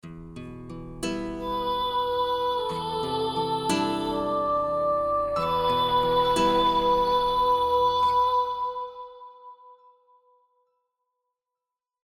Boy soprano Genre: World music Composer/Artist
Plays end of the track